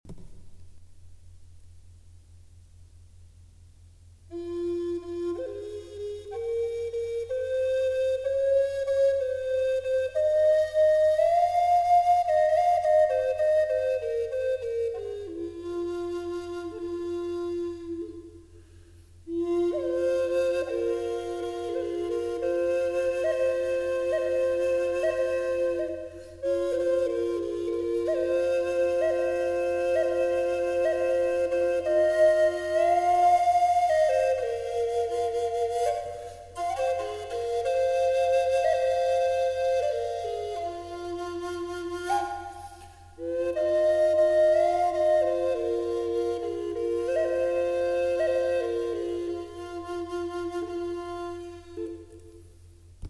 Double Flûte amérindienne • Fa
Cette flûte amérindienne en Fa (432 Hz) offre un son profond et apaisant, parfait pour accompagner vos moments de méditation, de voyage sonore ou de création musicale intuitive. Sa tonalité invite à un doux voyage intérieur.
• Note : Fa, accordée en 432 Hz
• Gamme harmonique mineur
flute-Fa-4-double.mp3